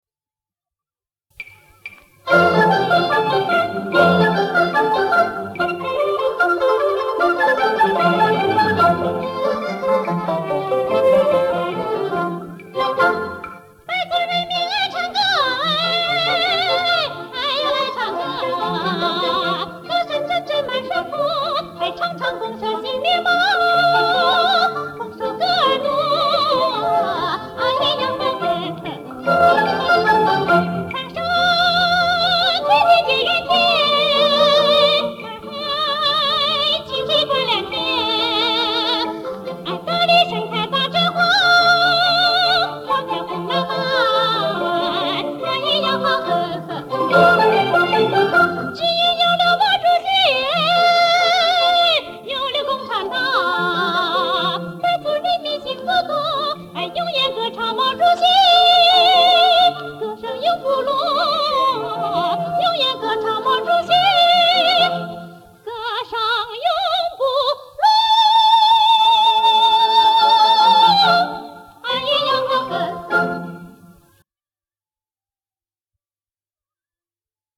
历史录音